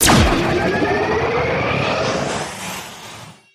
Appear_Scatter_Sound_Final.mp3